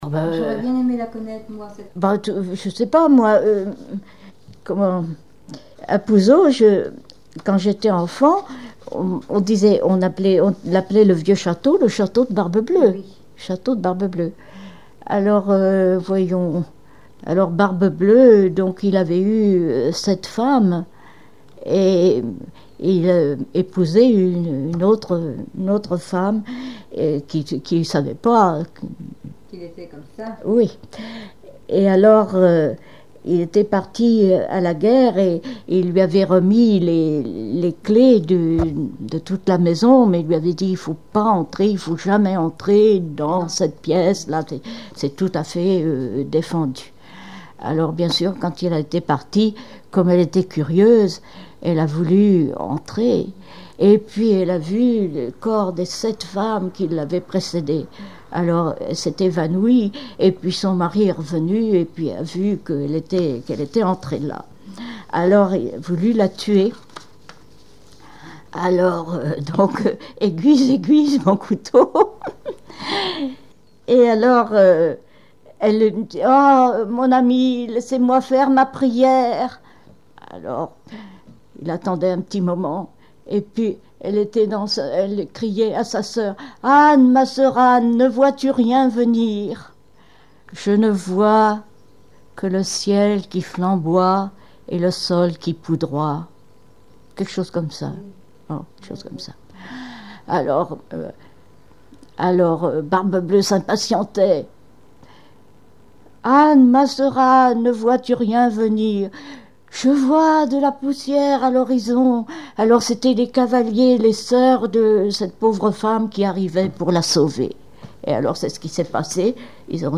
Genre conte
Témoignages et chansons